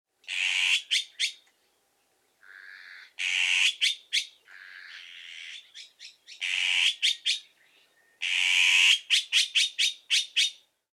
オナガ｜日本の鳥百科｜サントリーの愛鳥活動
「日本の鳥百科」オナガの紹介です（鳴き声あり）。